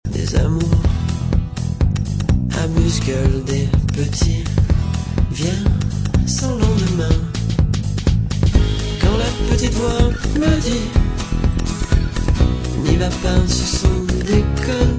électro-pop